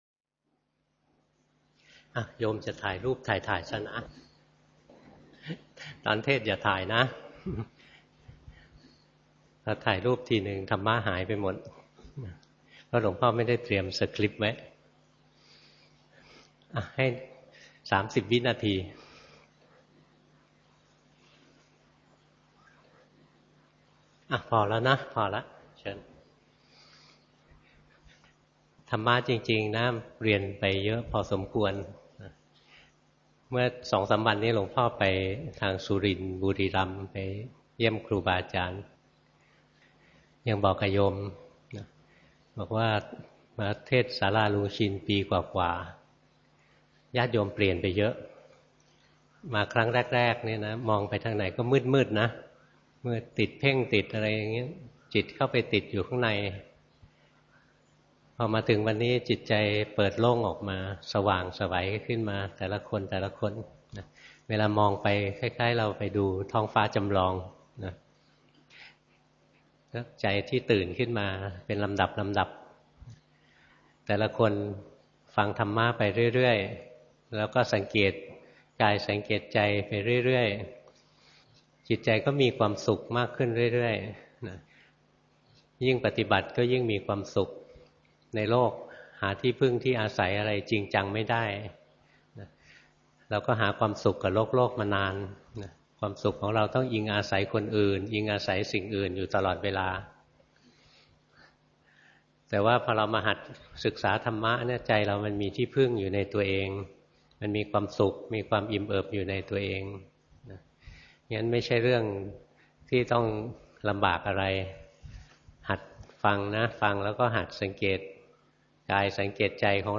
สวนสันติธรรม จ.ชลบุรี